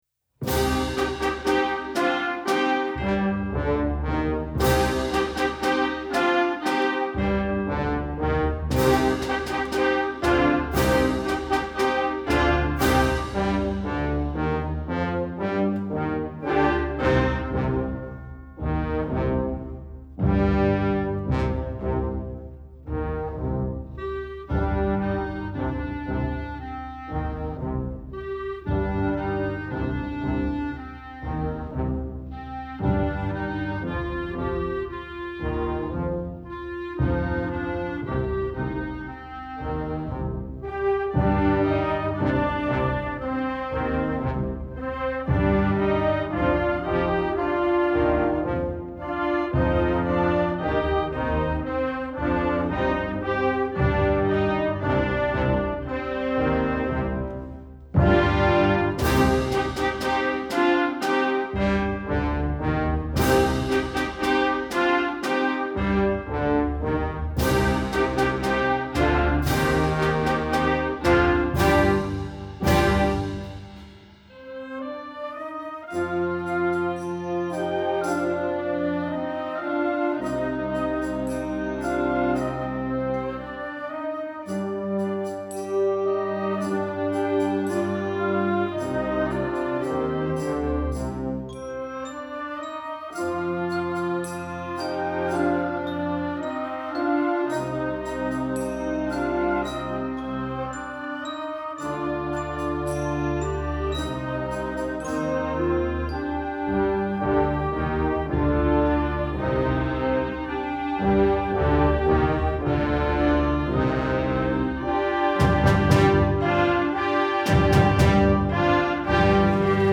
Gattung: Jugendwerk
3:02 Minuten Besetzung: Blasorchester PDF